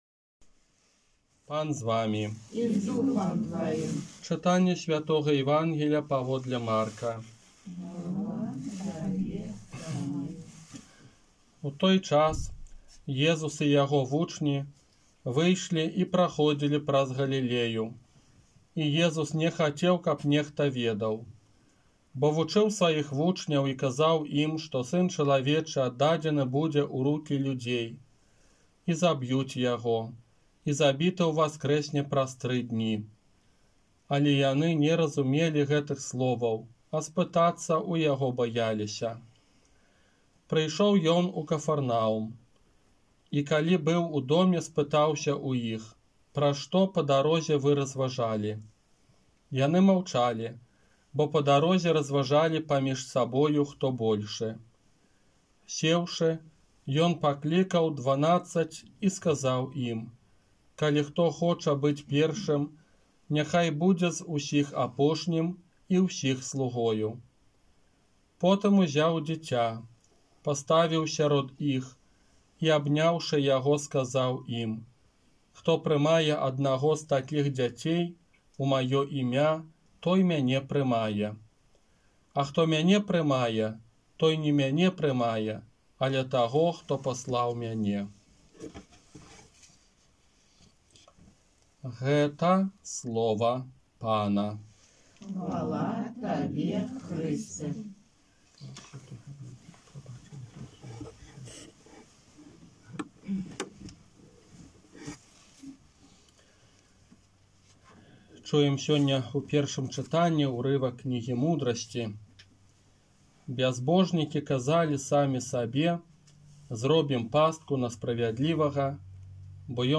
ОРША - ПАРАФІЯ СВЯТОГА ЯЗЭПА
Казанне на дваццаць пятую звычайную нядзелю